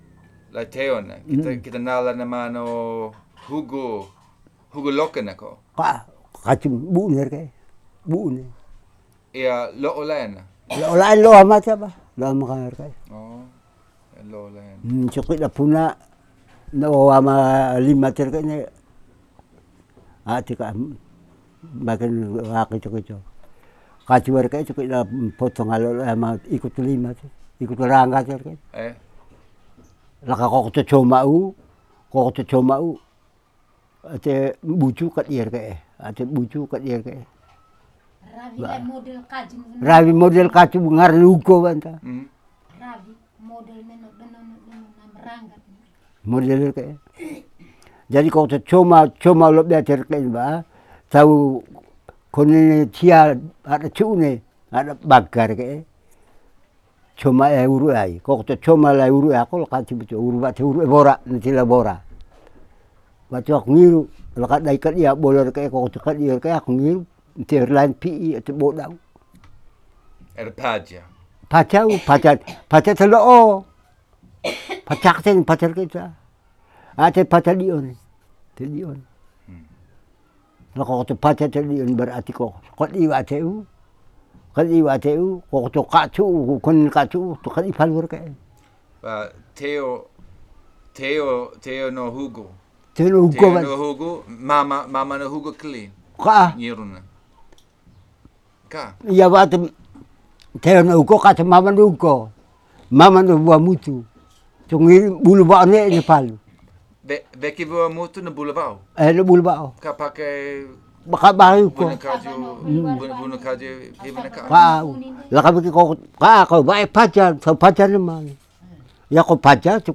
Recorded with an AT Lavelier mic
Palu'e, Flores, Nusa Tenggara Timur, Indonesia. Recording of man from kampong Kaju keri, Keli domain, done in kampong Mata mere.